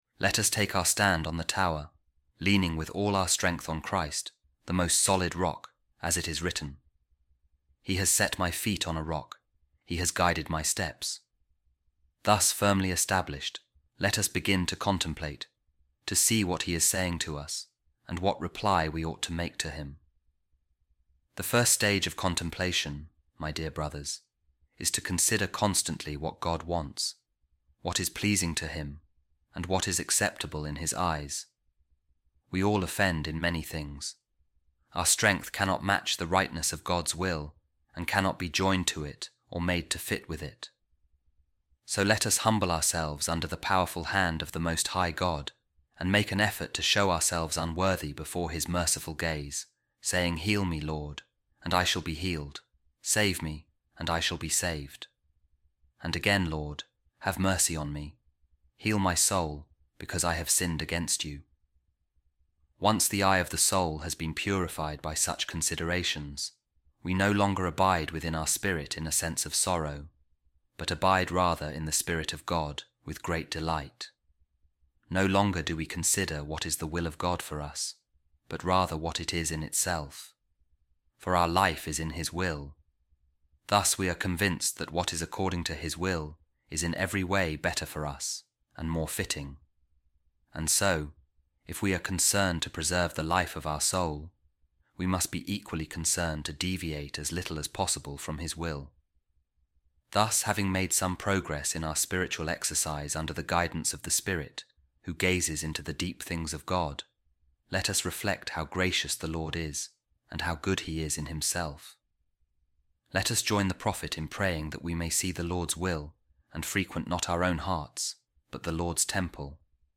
A Reading From The Sermons Of Saint Bernard | On Degrees Of Contemplation